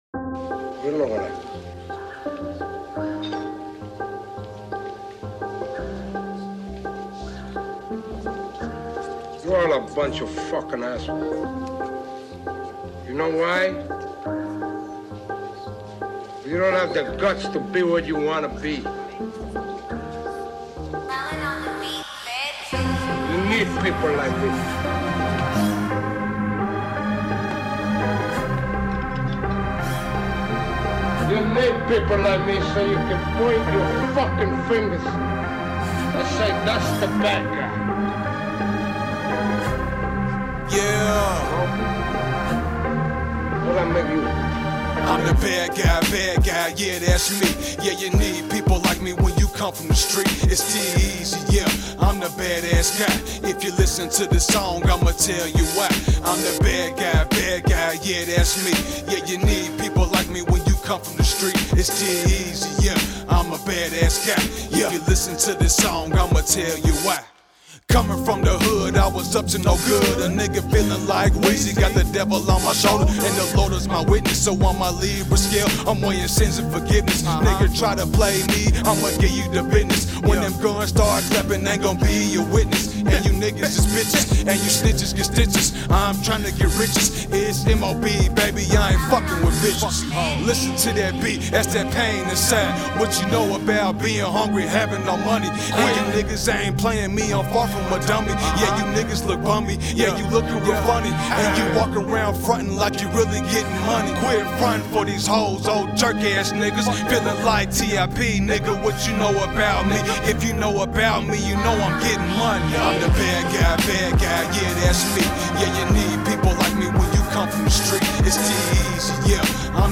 Hiphop
Description : Upcoming Rapper from Lexington, Ky.